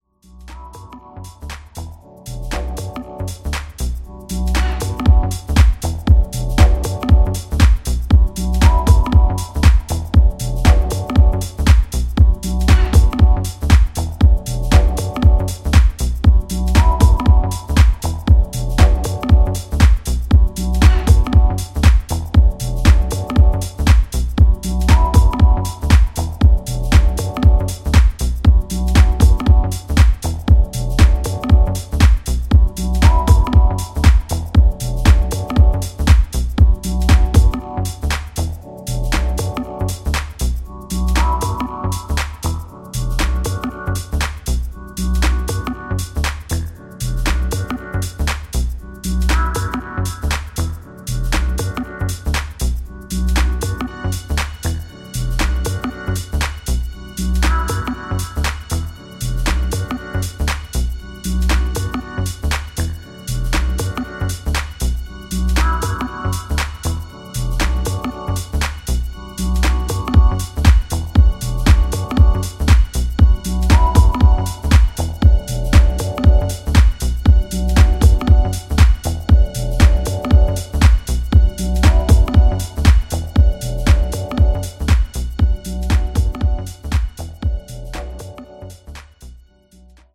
US産らしい厚みあるミニマル/ハウスを融合したナイスなデトロイト・ディープ・ハウスを展開する全4トラック。
ジャンル(スタイル) HOUSE / DEEP HOUSE